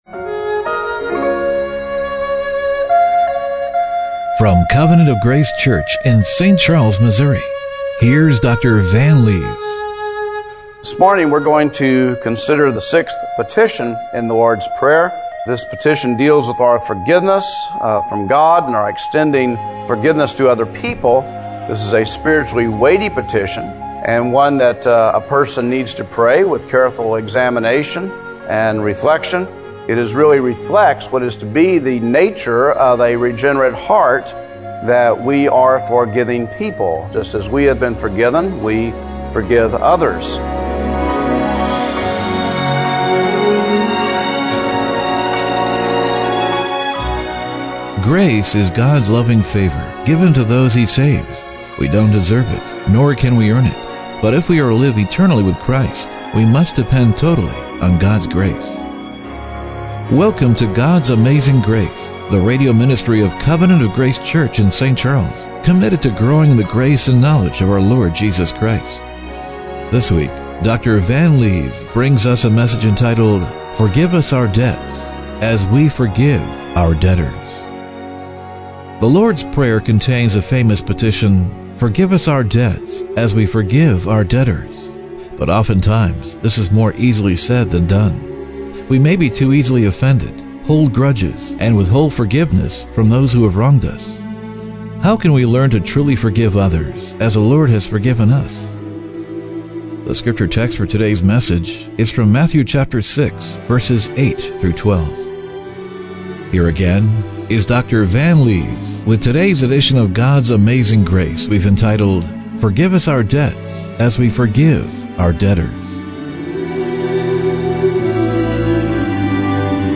Matthew 6:9-13 Service Type: Radio Broadcast How can we learn to truly forgive others